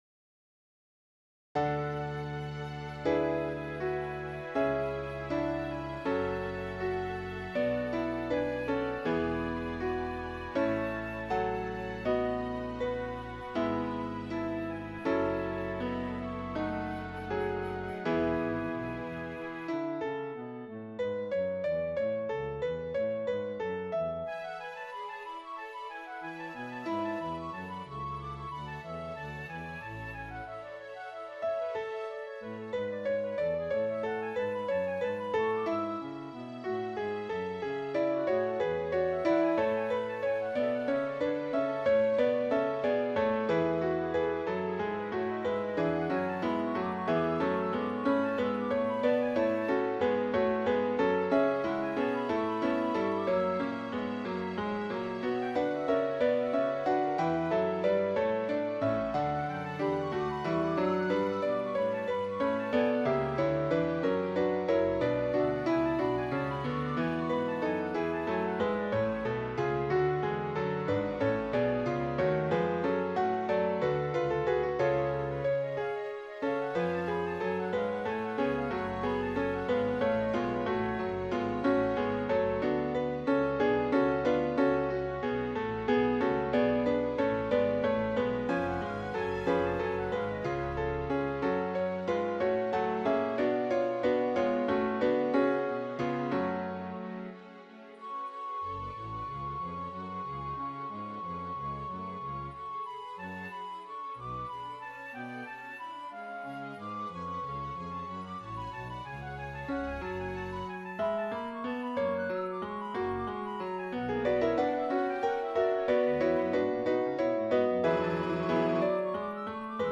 MP3 Dateien von allen Chorstücken nach Register
BWV234-6 Cum Sancto_SATB.mp3